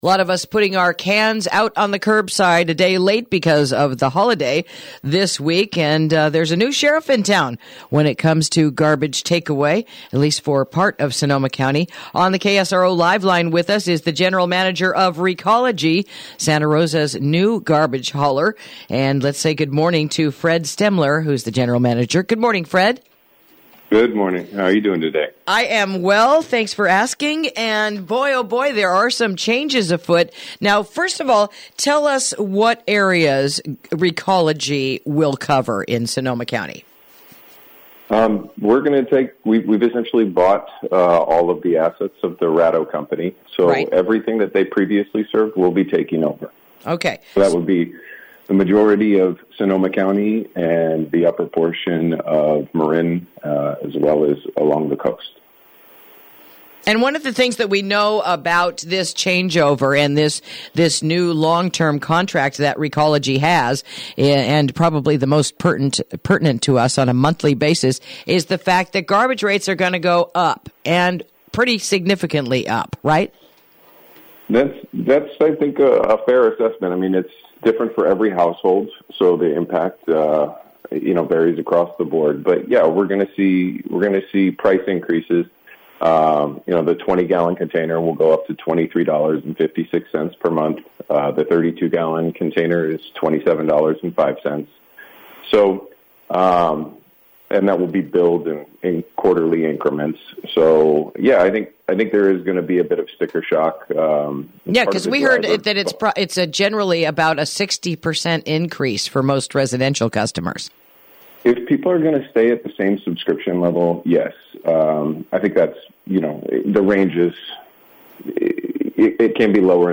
Interview: Starting January 1st Recology Takes Over for Waste Removal